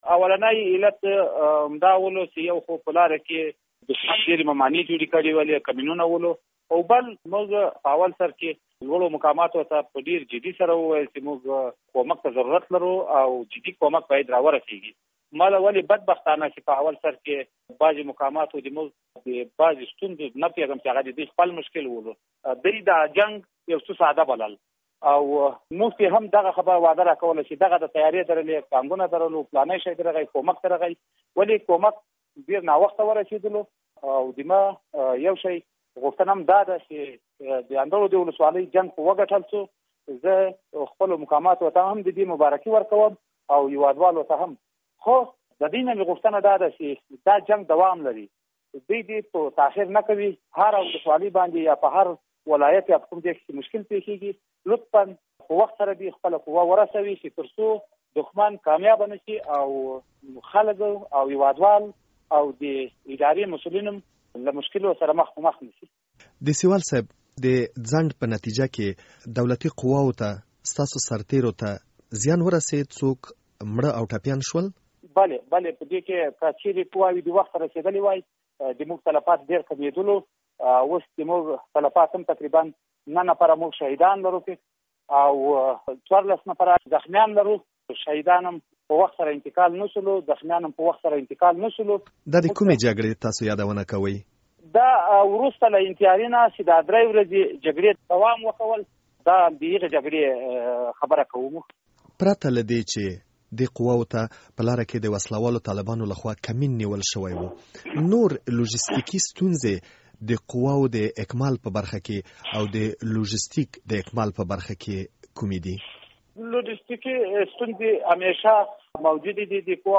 له ښاغلي دیسیوال سره مرکه